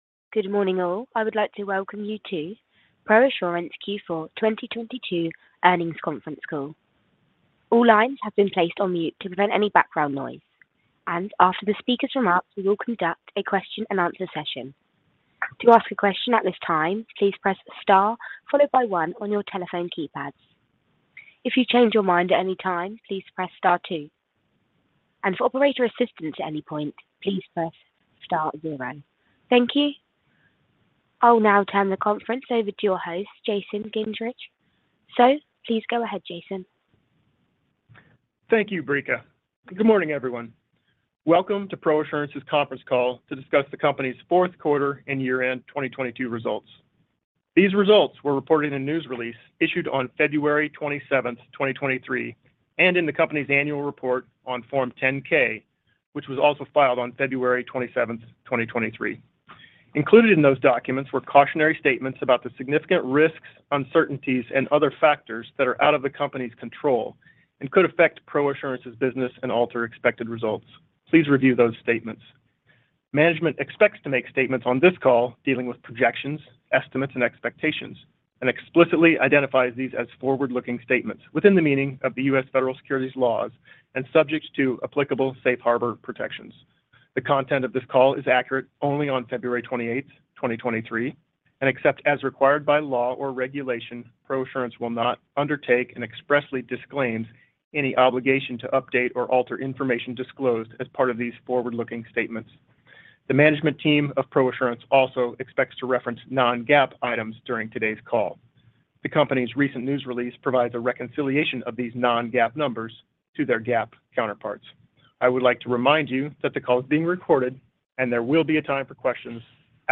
Q4 Earnings Conference Call | ProAssurance Corporation